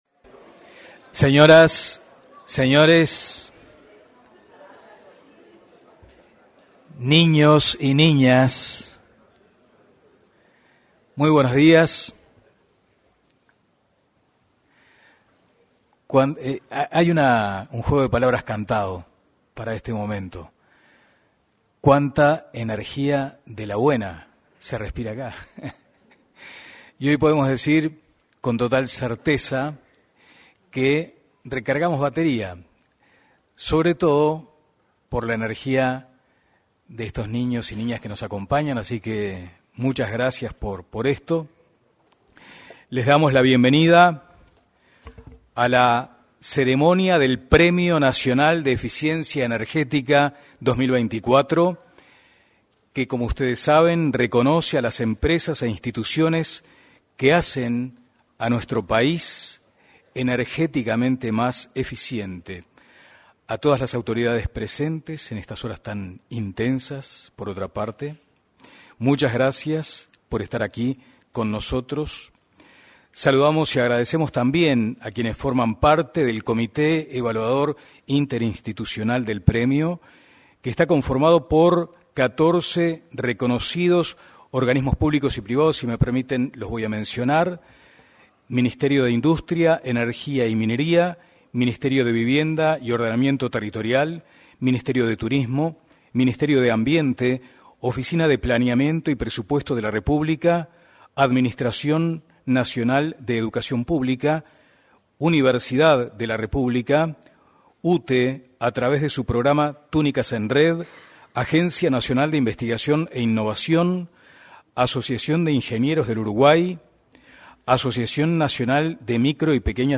Este viernes 25, se realizó, en el auditorio de la Torre Ejecutiva anexa, la ceremonia de entrega del Premio Nacional de Eficiencia Energética.
En la oportunidad, se expresaron la ministra de Industria, Energía y Minería, Elisa Facio, y el director nacional de Energía, Christian Nieves.